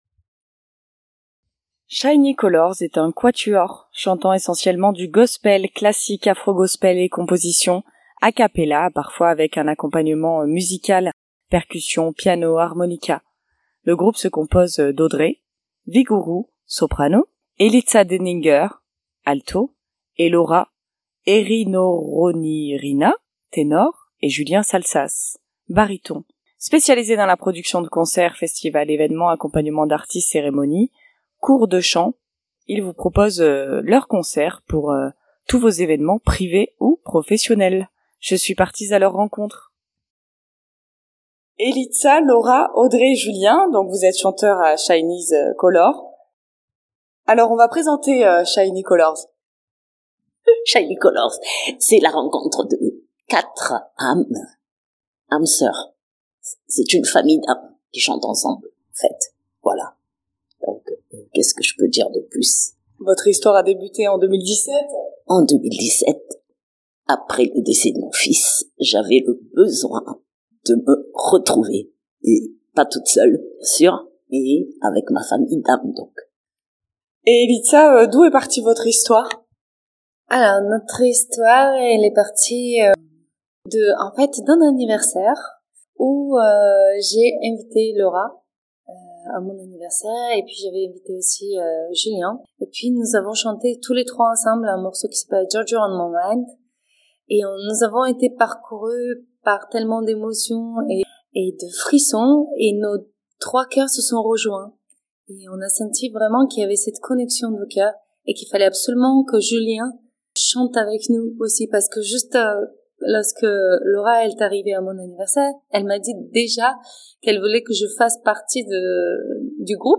A la rencontre du groupe Shiny Colors (8.73 Mo) Shiny Colors est un quatuor chantant essentiellement du Gospel (classique, afro Gospel et compositions) a capella avec parfois un accompagnement musical (percussions, piano, harmonica).